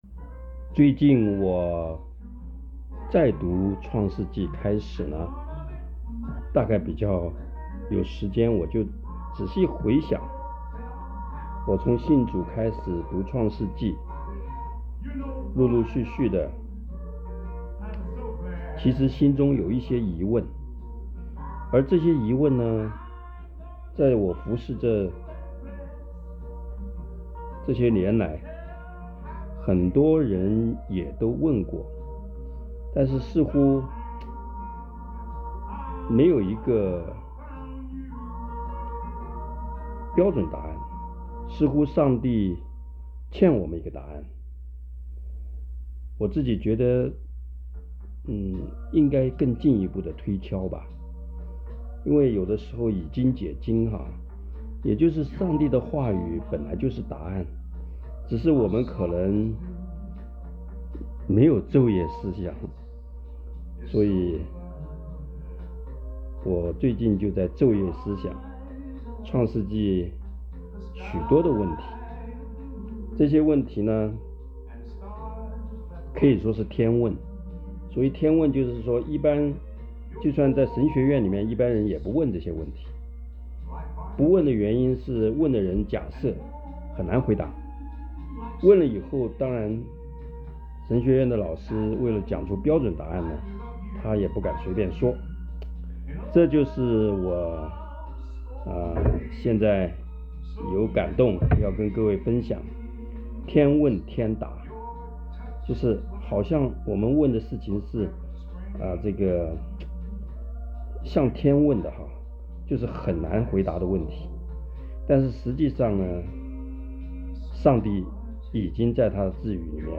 短讲